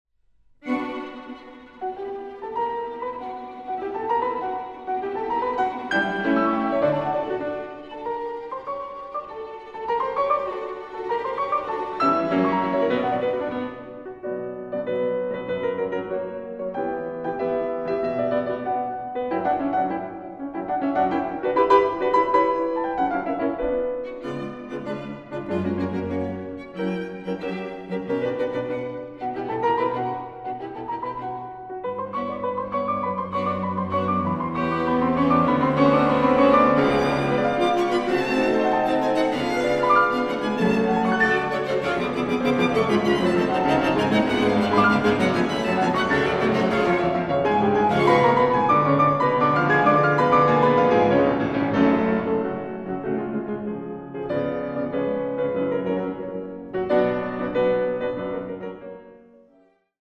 Klavierquartett